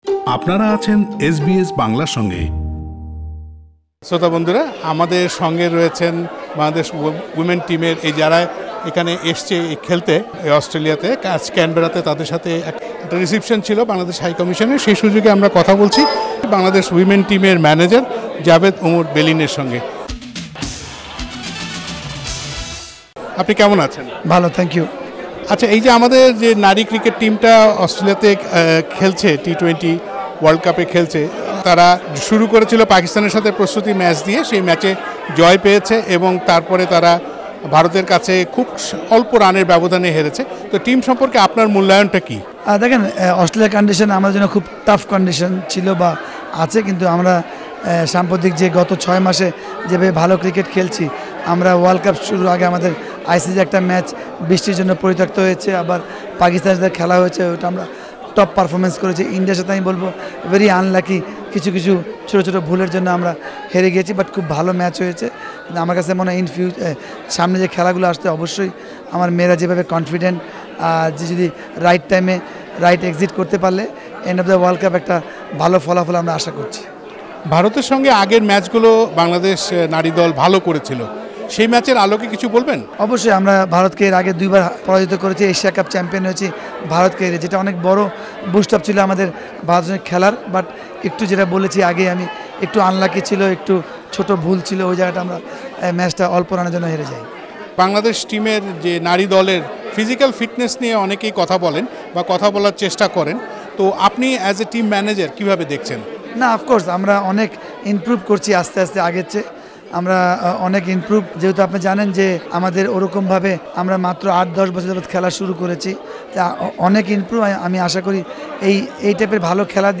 টিমের পারফরমেন্স নিয়ে কথা হয় দলের ম্যানেজার জাভেদ ওমর বেলিনের সঙ্গে। তিনি নারী টীম এর পারফরমেন্স সহ নানান বিষয়ে নিয়ে কথা বলেন এস বি এস বাংলাকে। জাভেদ ওমর বেলিনের সাক্ষাৎকারটি শুনতে উপরের লিংকটিতে ক্লিক করুন।